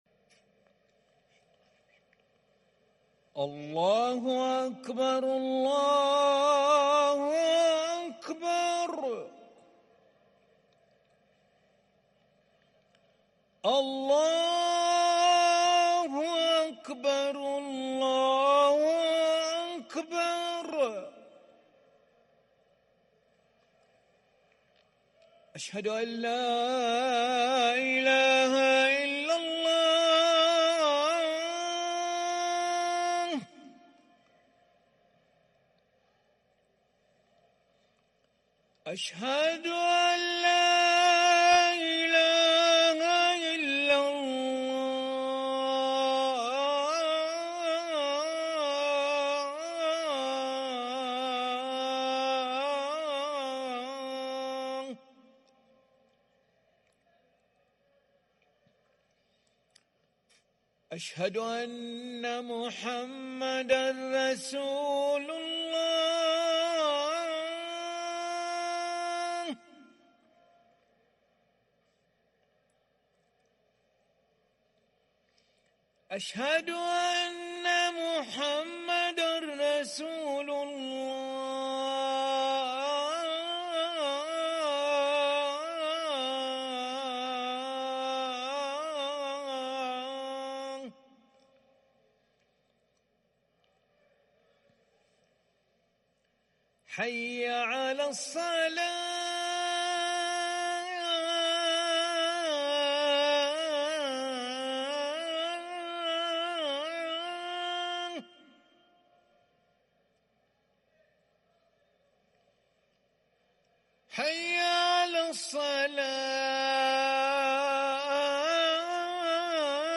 أذان العشاء للمؤذن علي ملا الأحد 6 ربيع الأول 1444هـ > ١٤٤٤ 🕋 > ركن الأذان 🕋 > المزيد - تلاوات الحرمين